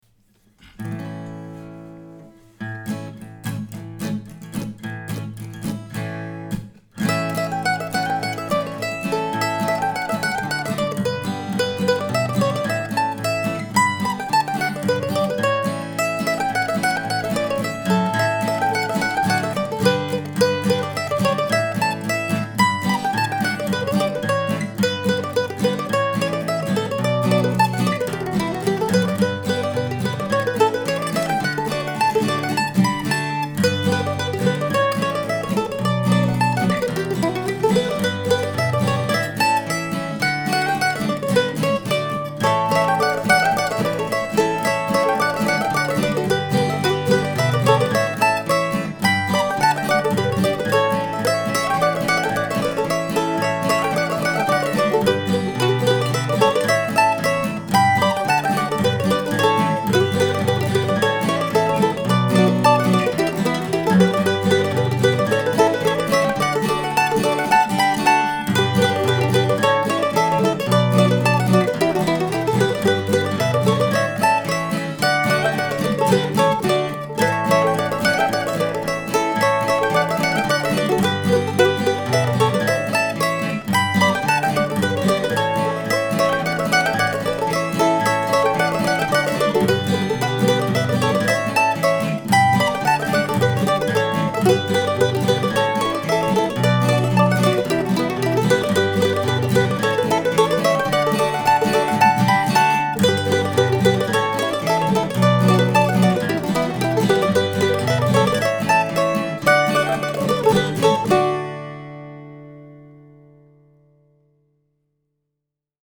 This week's tune should probably go a little faster than it is presented here. Hopefully the rhythmic, um, inconsistencies won't be bother you as much as they do me.
There's a kind of cool effect (at least to my ears) when the harmony part comes in because it's recorded with the guitar and melody parts playing out loud in the room, thereby bleeding into the harmony track. Oh, and there's a neighborhood lawn mower somewhere in the background too.